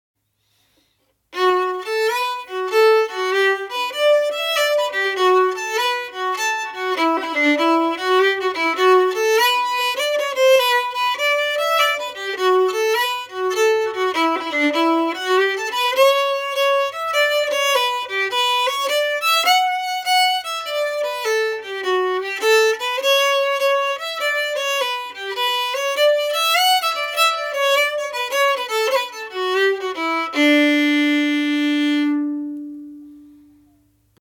The Snowy Path melody with little variations (MP3)Download
the-snowy-path-melody-with-little-variations.mp3